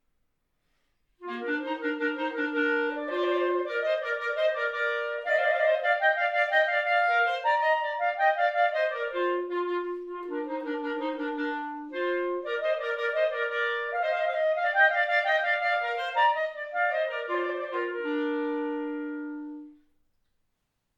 Besetzung: 2 Klarinetten